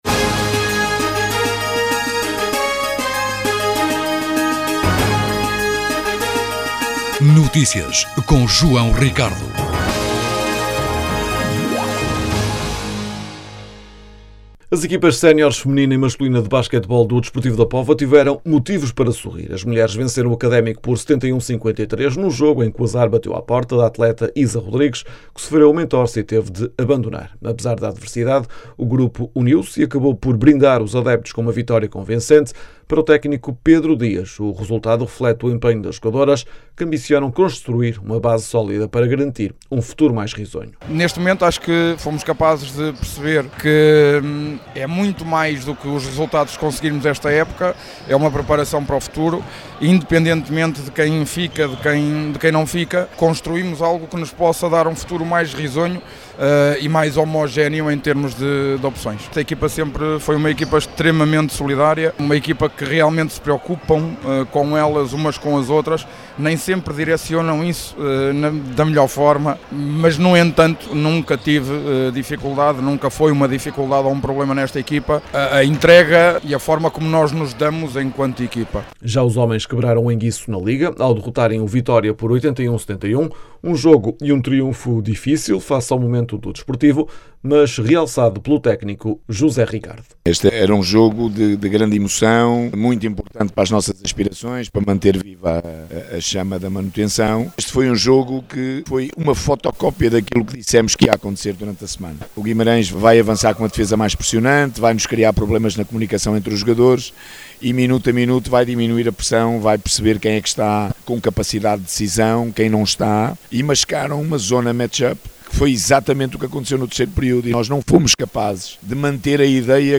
O Vereador da Cultura, Paulo Vasques, explicou o principal objetivo que este concurso pretende atingir.
As declarações podem ser ouvidas na edição local.